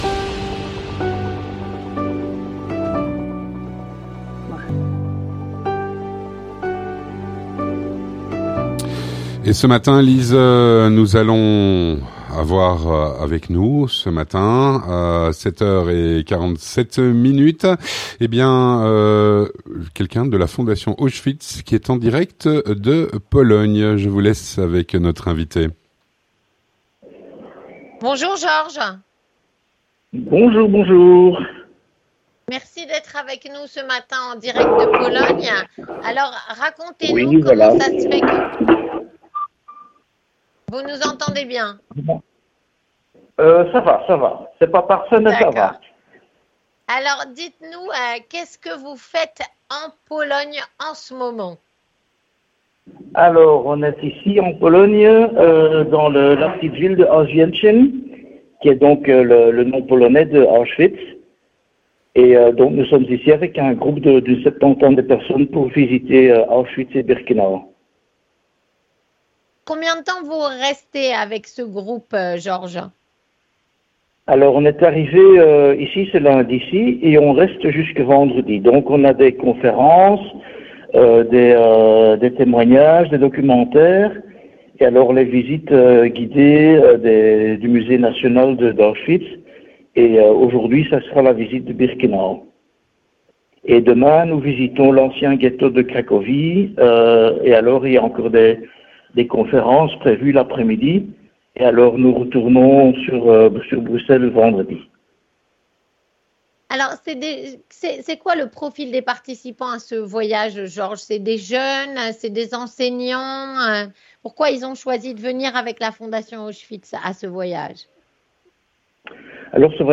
En voyage à Auschwitz-Birkenau, la "Fondation Auschwitz" nous trace les parallèles qui existent entre les massacres du 07 Octobre et La Shoah.